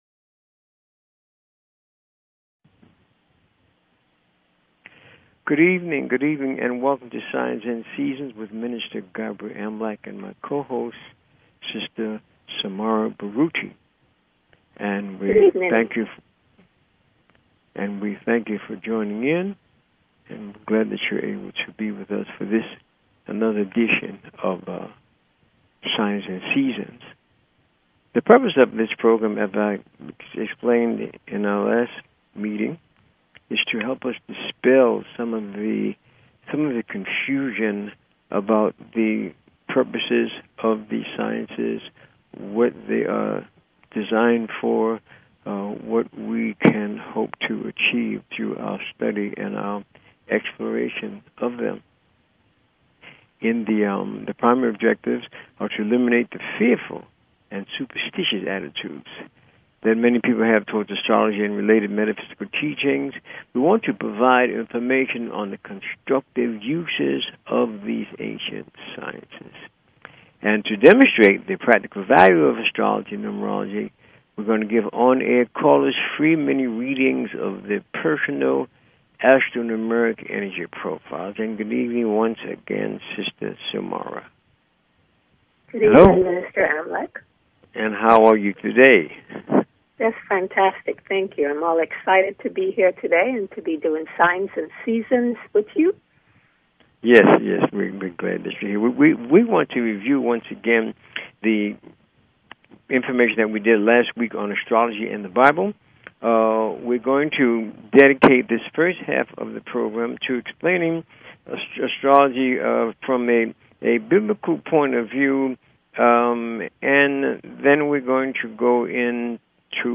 Talk Show Episode, Audio Podcast, Signs_and_Seasons and Courtesy of BBS Radio on , show guests , about , categorized as
And to demonstrate the practical value of Astrology and Numerology by giving on air callers FREE mini readings of their personal Astro-numerica energy profiles.